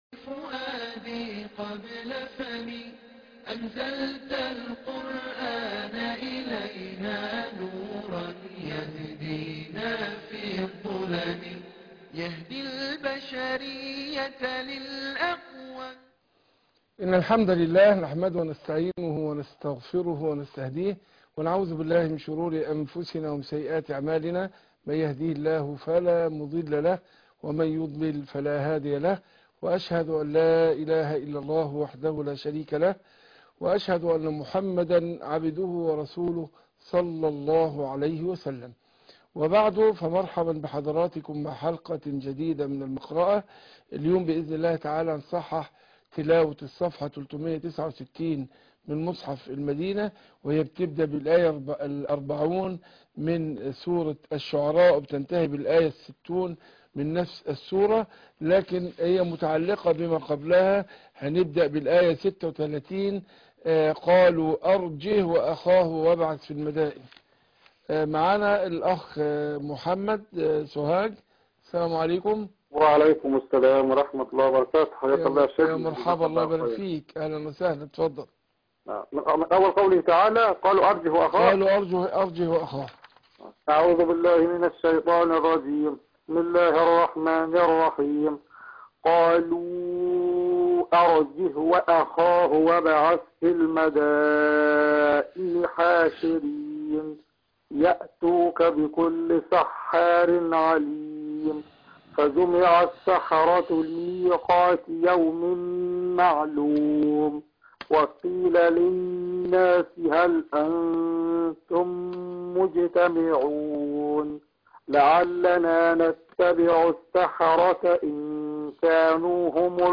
المقرأة - سورة الشعراء ص369